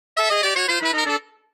Смешные и комичные звуковые эффекты для детских видео в mp3